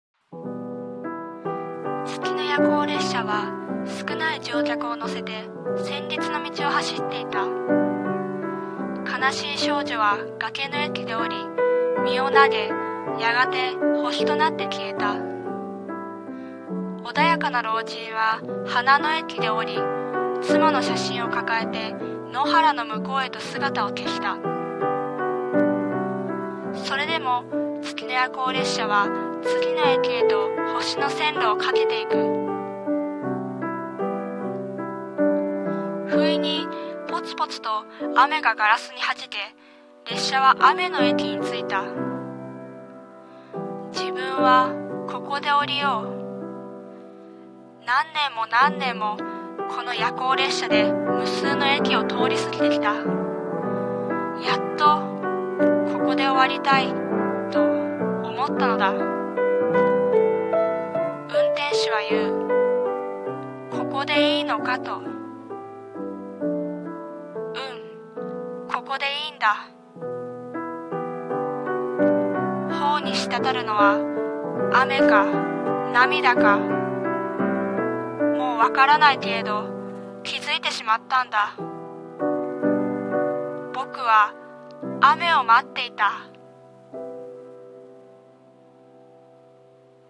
さんの投稿した曲一覧 を表示 【声劇台本】雨の夜行列車